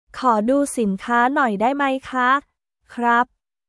コー ドゥー シンカー ノーイ ダイ マイ カ／クラップ